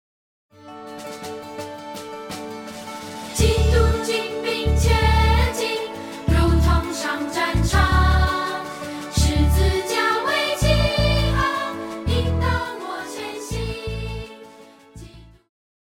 Pop chorus,Children Voice
Band
Hymn,POP,Christian Music
Voice with accompaniment